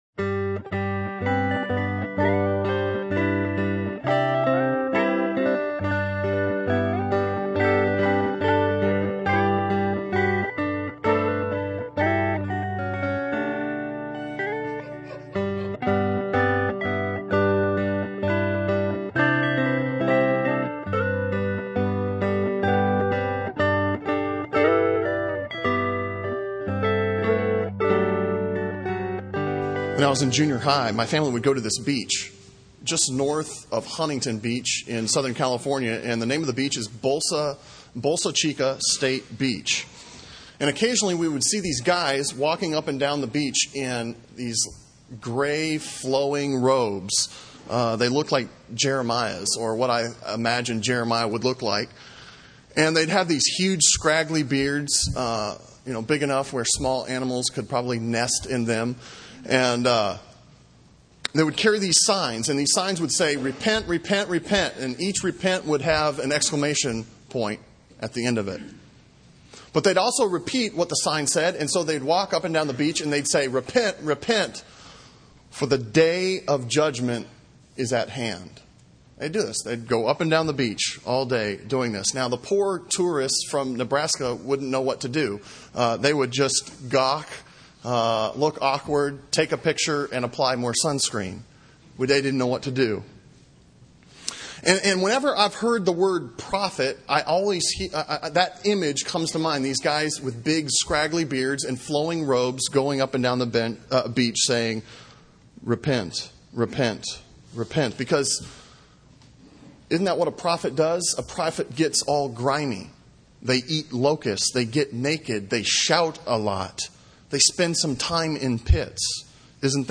Sermon Audio from Sunday
Sermon on Hosea 12:10-14 from October 5